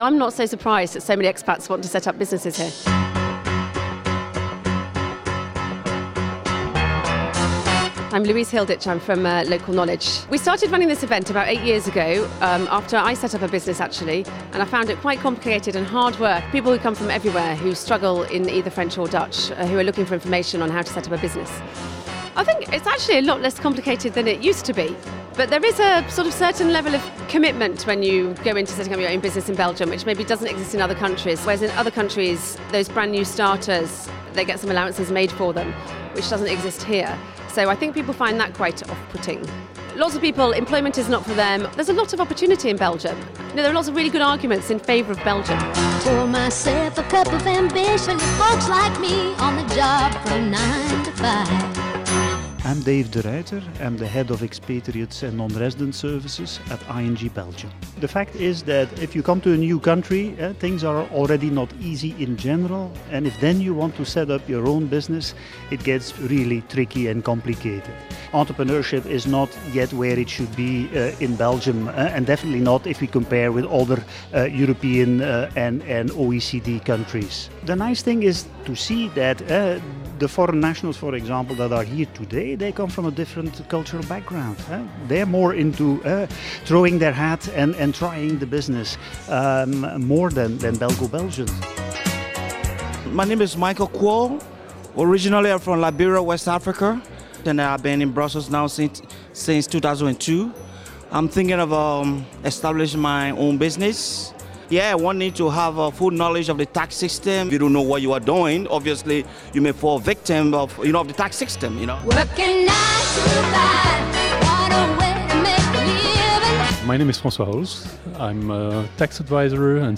We attended a seminar yesterday and spoke to experts about setting up a business, and to some people who've already done it or are about to take the plunge.